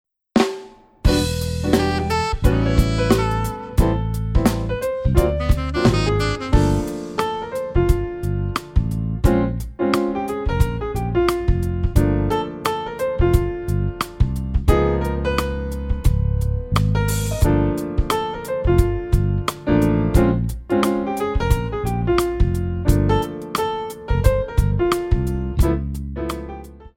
Modern / Jazz
4 bar intro
R&B Jazz